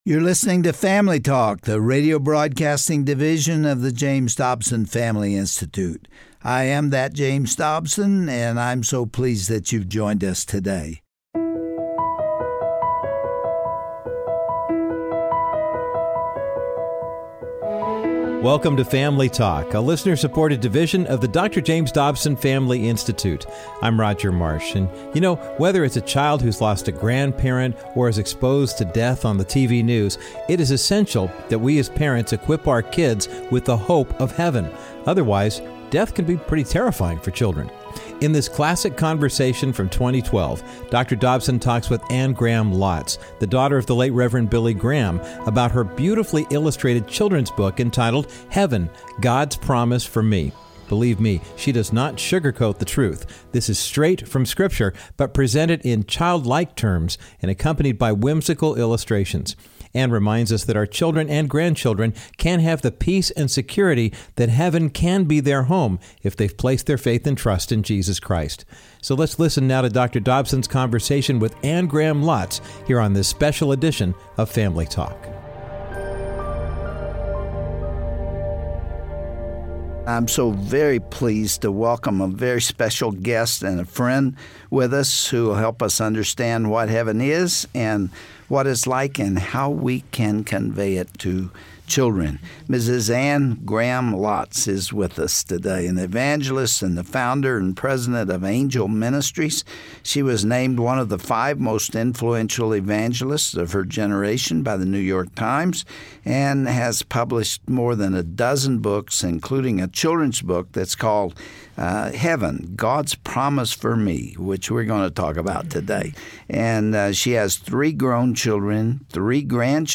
On today’s edition of Family Talk, Dr. James Dobson speaks with Anne Graham Lotz about her children’s book, Heaven: God's Promise for Me. In her beautifully illustrated project, Anne reminds us that Heaven is for children also, and death is not something to fear when you have put your faith in Jesus.
Host Dr. James Dobson